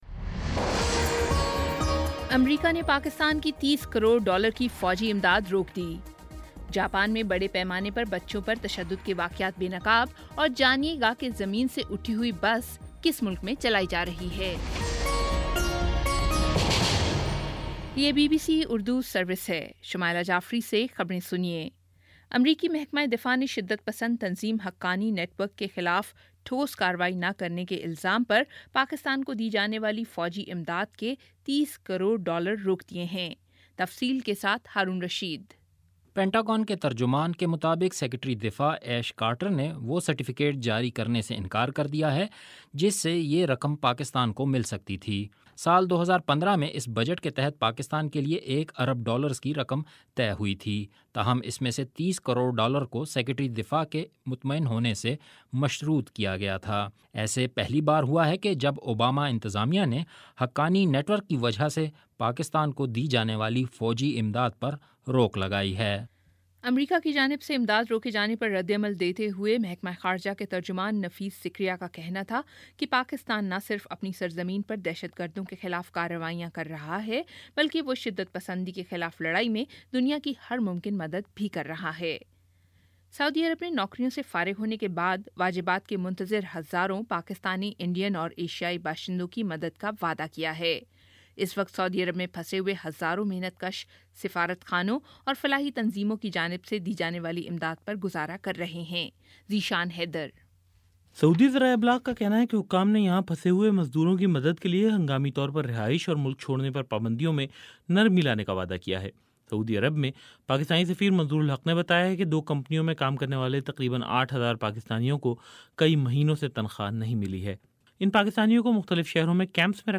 اگست 04 : شام سات بجے کا نیوز بُلیٹن